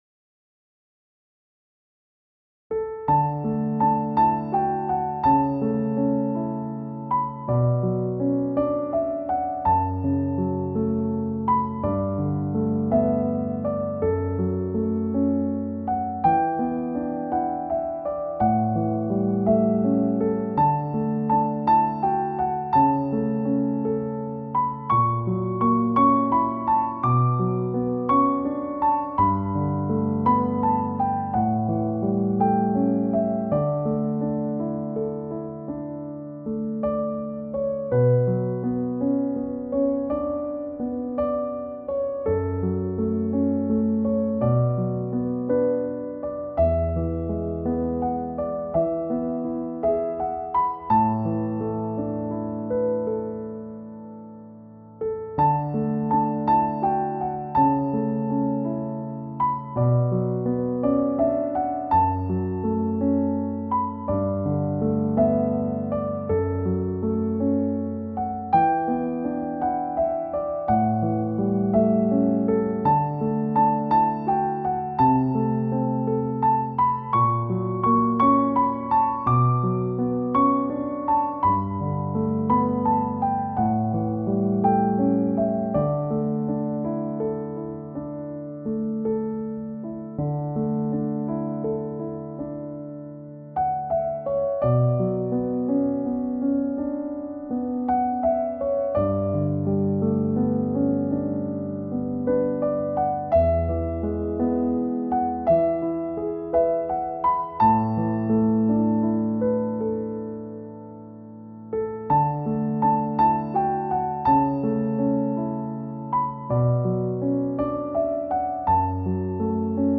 鋼琴曲：一滴露珠靜靜的停在葉尖 | 法輪大法正見網